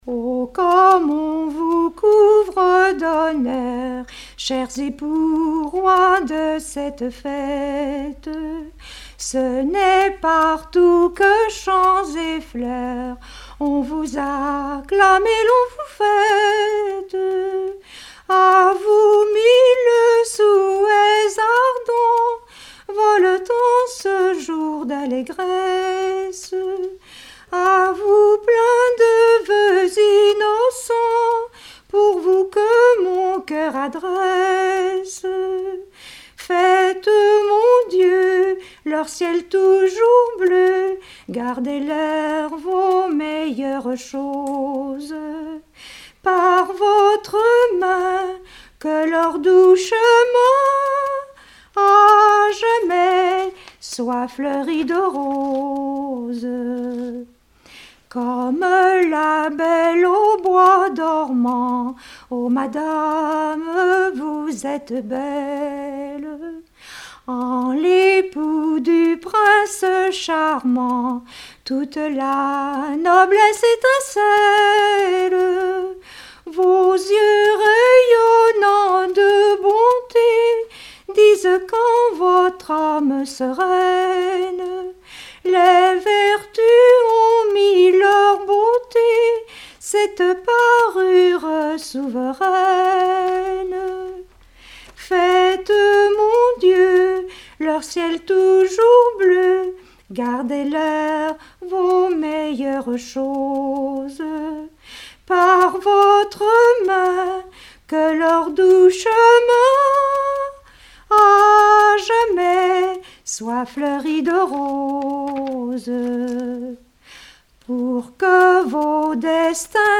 Collectif de chanteurs du canton - veillée (2ème prise de son)
Pièce musicale inédite